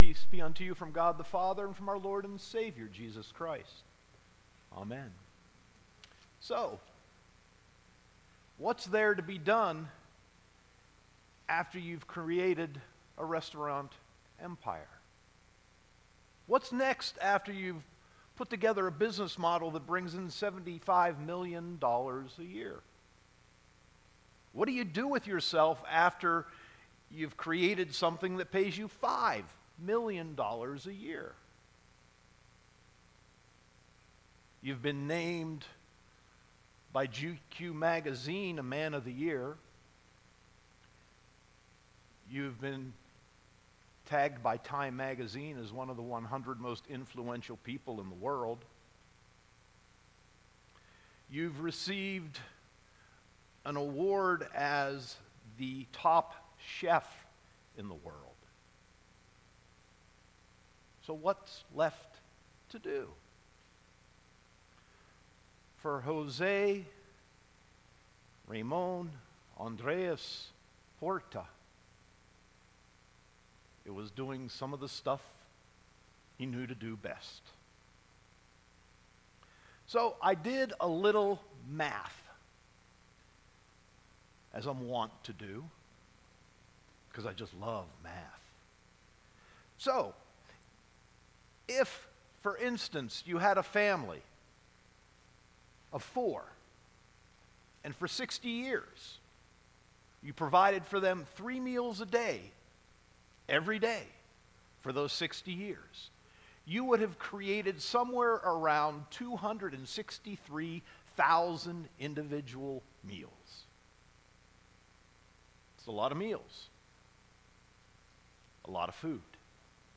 Sermon 9.23.2018